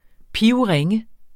Udtale [ ˈpiwˈʁεŋə ]